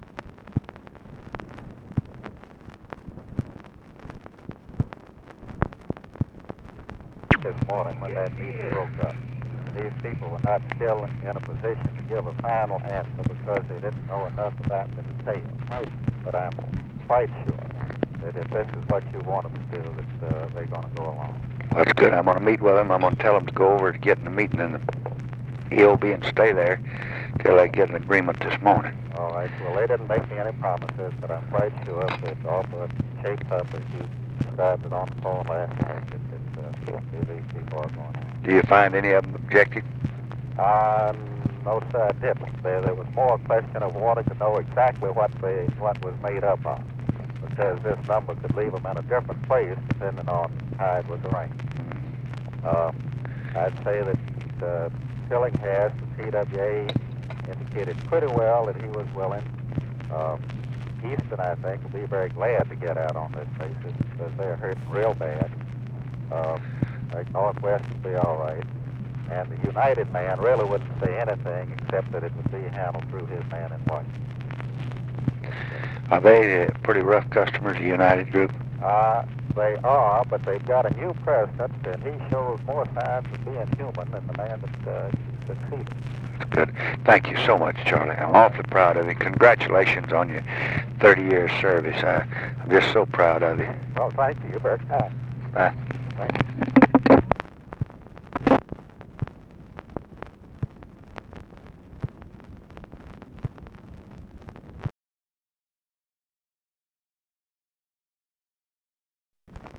Conversation with CHARLES MURPHY, July 29, 1966